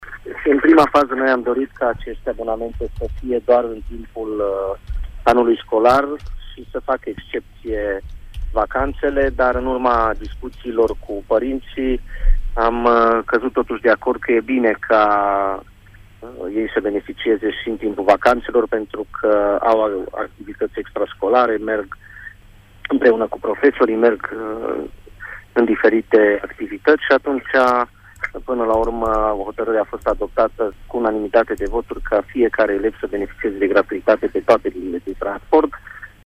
Hotărârea Consiliului Local a fost adoptată în unanimitate, după consultările cu elevii și părinții. Abonamentele vor fi valabile tot anul, nu doar în timpul cursurilor, a precizat viceprimarul Claudiu Maior: